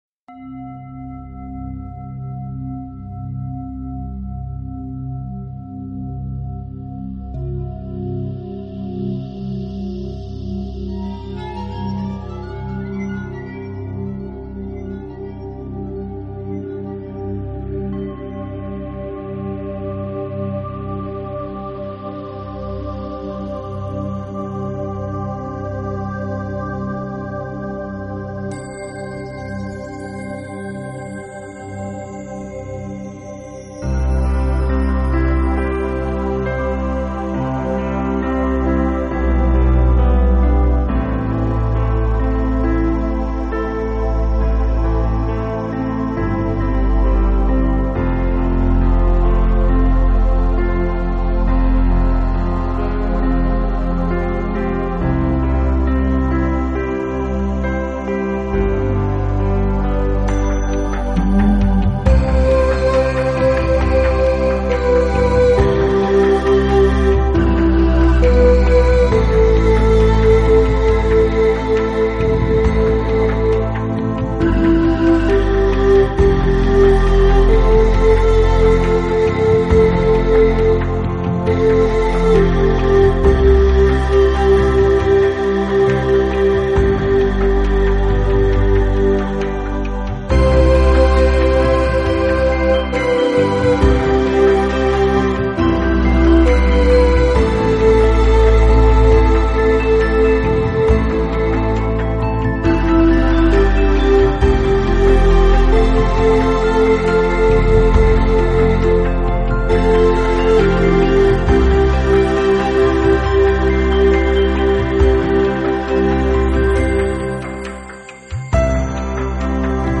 【纯音乐】世界器乐精选集VA